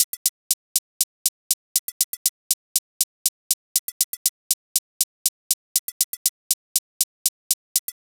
Session 14 - Hi-Hat 02.wav